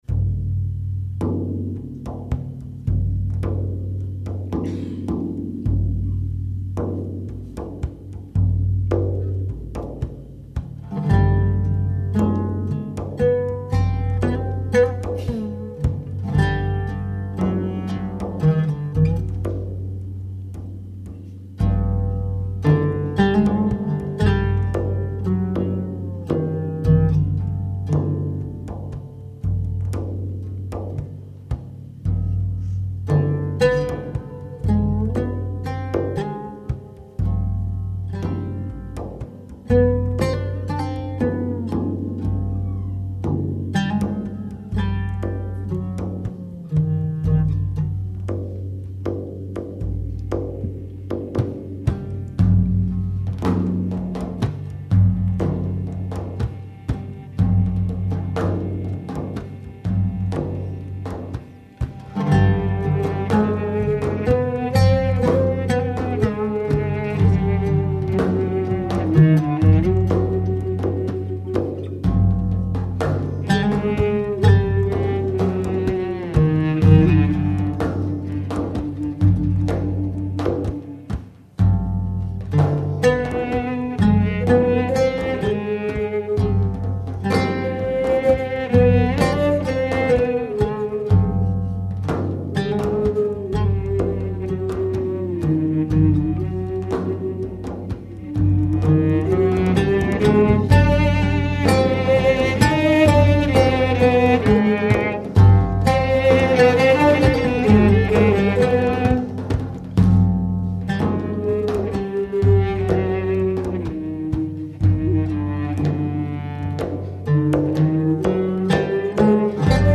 The Loft 5/17/08
Native American flute
cello
frame drums, darbouka, and cajon